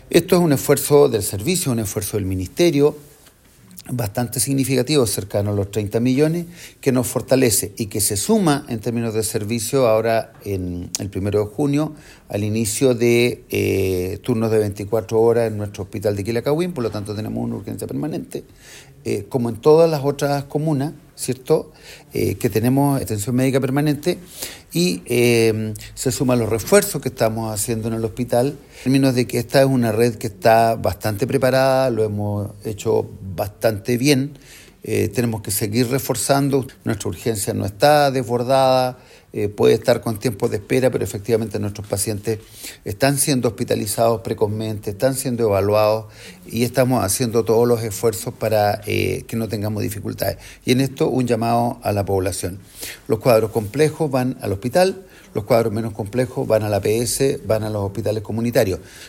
El Director del SSO destacó la inversión que se realiza para la atención en la red pública de salud, que viene a complementar las mejoras que se han realizado para afrontar la temporada de invierno.